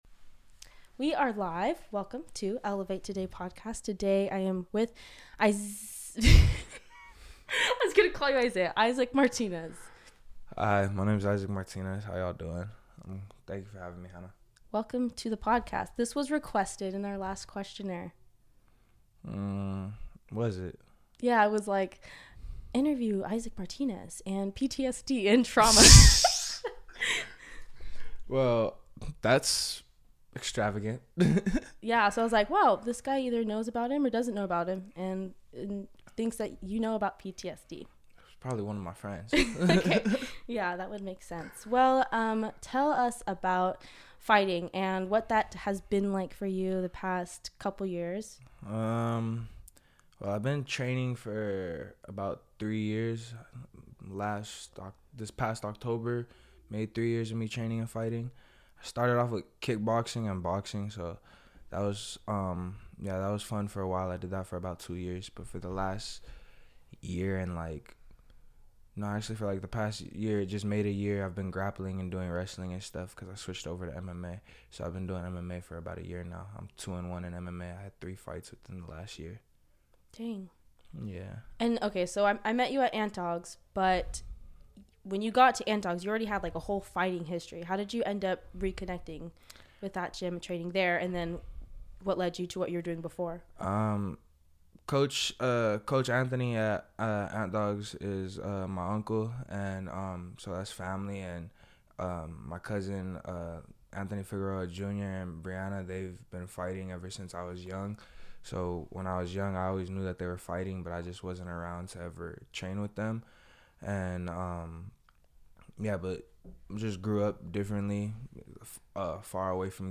In this podcast I interview